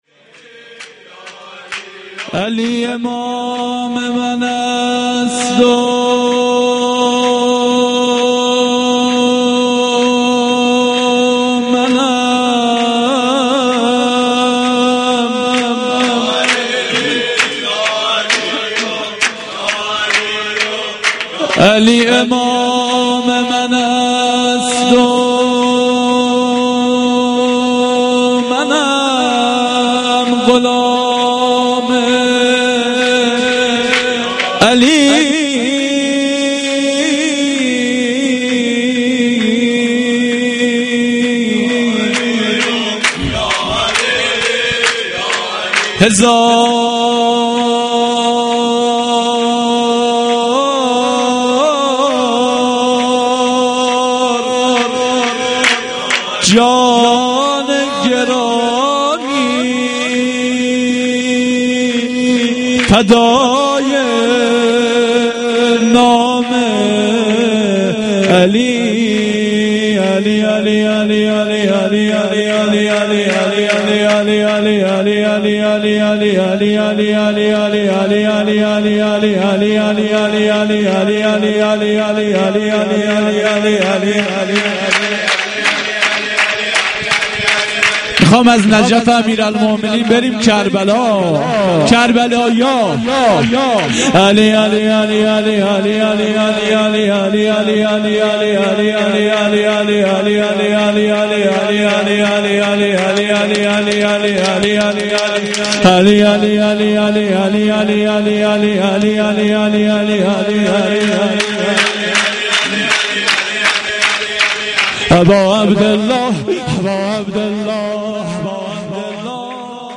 veladate-emam-hosein-a-93-sorood-part1.mp3